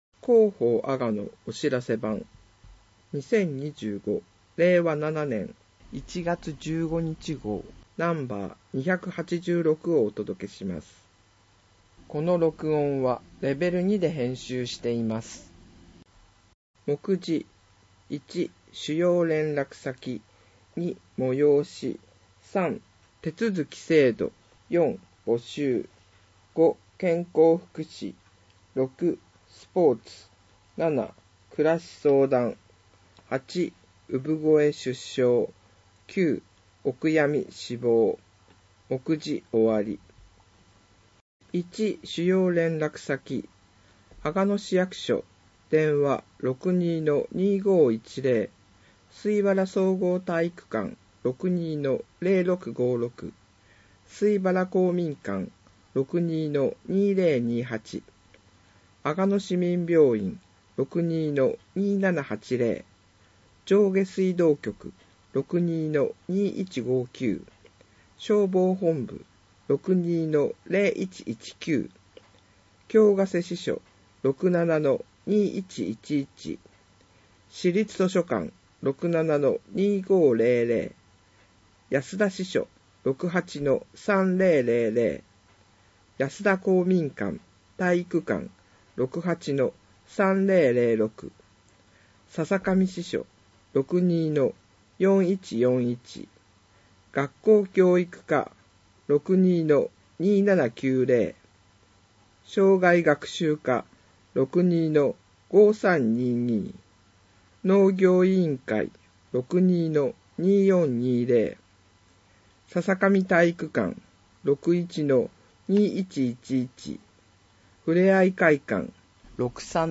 市では、視覚に障がいのある方向けに、ボランティア団体「うぐいす会」の皆さんのご協力により、広報あがのを音声訳したCDを作成し、希望する方に配付しています。